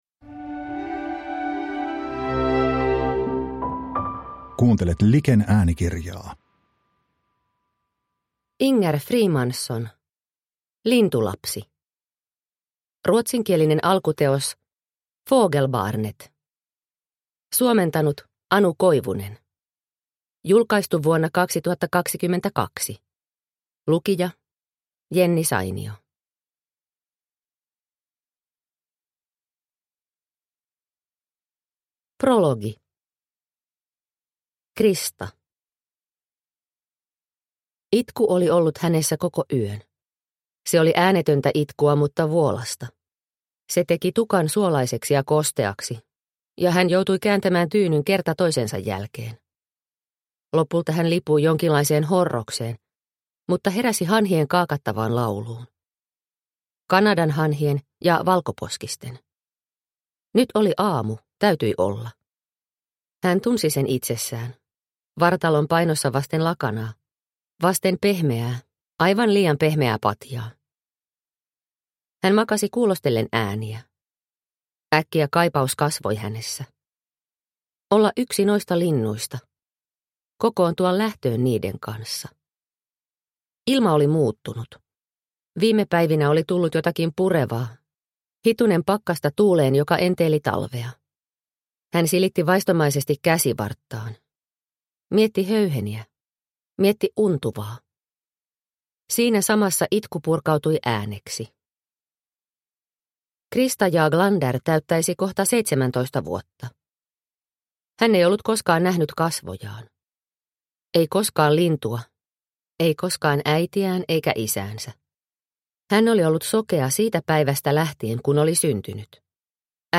Lintulapsi – Ljudbok – Laddas ner